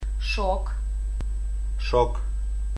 Molemmissa ilma kiertää melko vapaasti suussa ja kieli on melko alhaalla. Soinniton Ш puhalletaan ulos suusta (muistuttaa tuulen huminaa metsässä) ja soinnillinen Ж (joka muistuttaa ison lentävän kimalaisen tai kovakuoriaisen ääntä) muodostuu jo aikaisemmin ja tuntuu värähtelynä kurkussa.